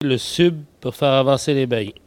Il chante pour faire avancer les boeufs
traction bovine